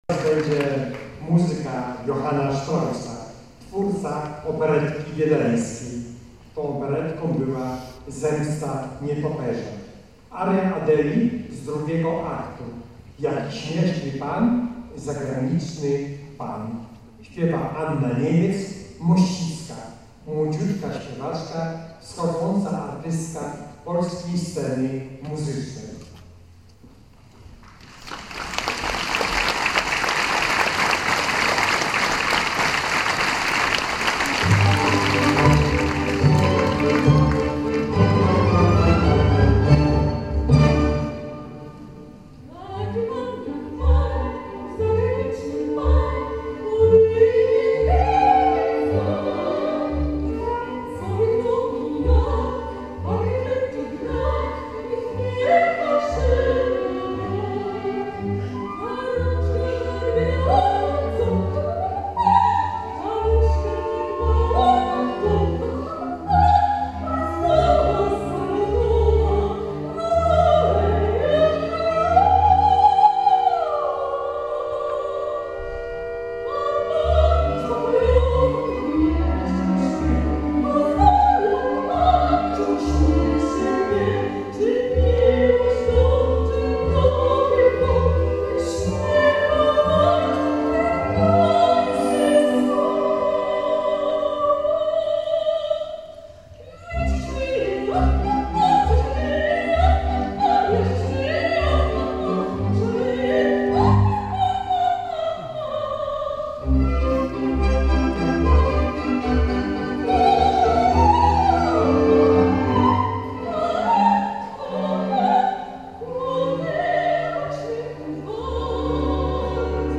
Posłuchaj mnie - utwory wykonane z towarzyszeniem orkiestry
aria Adeli